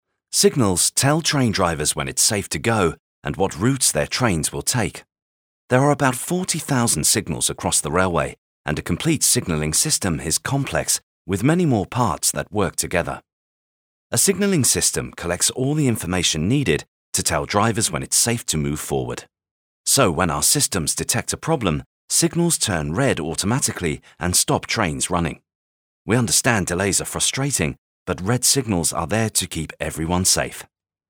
Male
Very versatile, warm, rich, authentic British accent, charismatic and friendly with a comedic twist of fun when needed!
Explainer Videos
Clear, Warm, Factual, Straight
Words that describe my voice are British, Warm, Conversational.